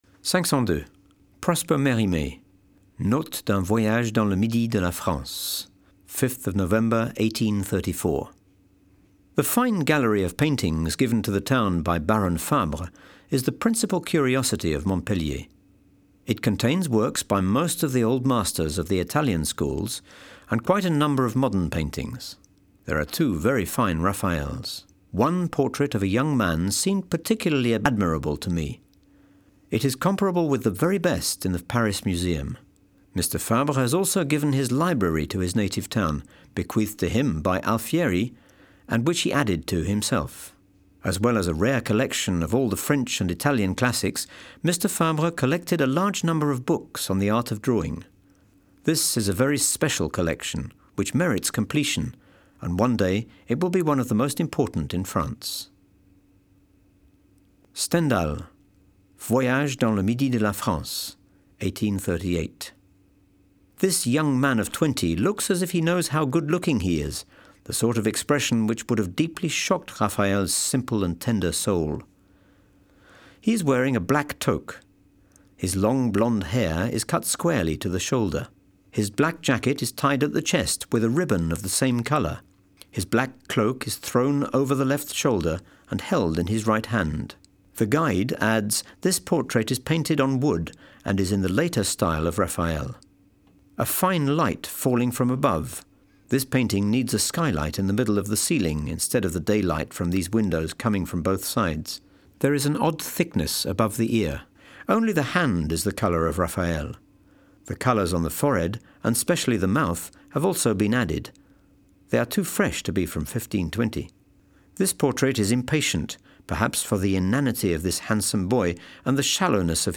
Audioguide FR